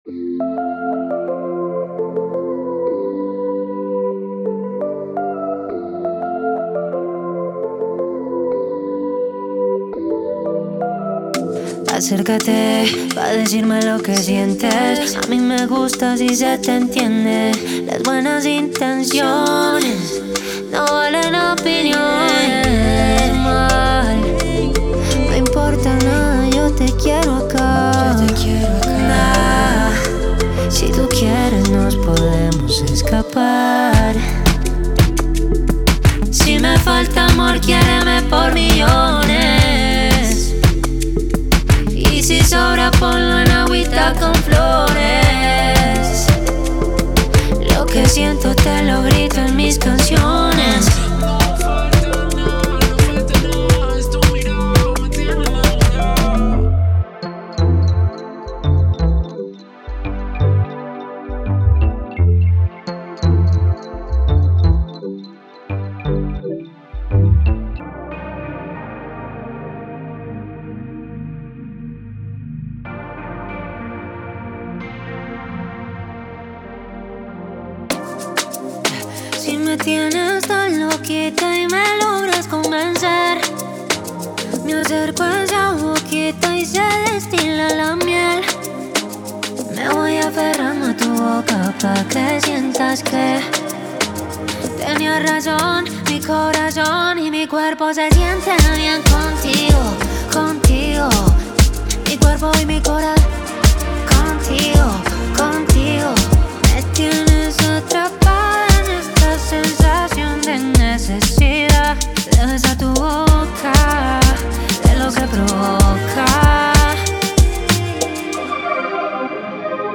Genre:Latin
デモサウンドはコチラ↓